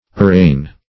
Arraign \Ar*raign"\, n.